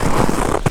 STEPS Snow, Walk 18.wav